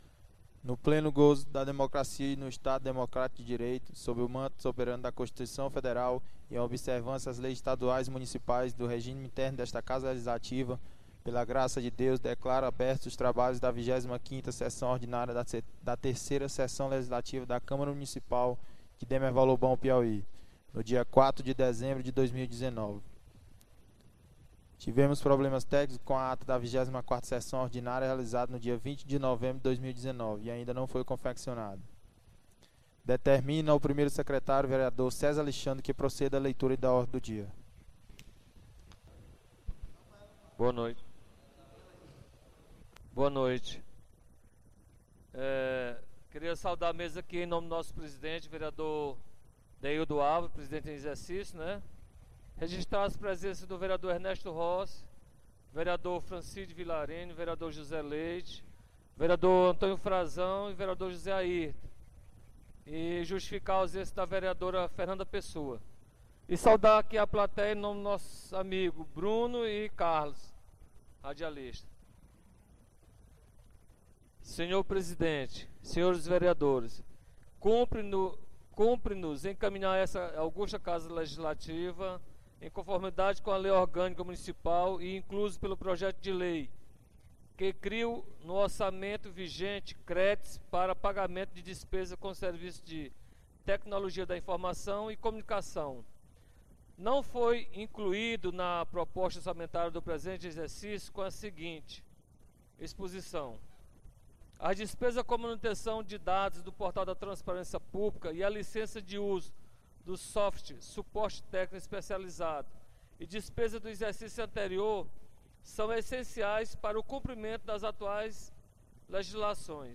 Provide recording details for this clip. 25ª Sessão Ordinária 04 de Dezembro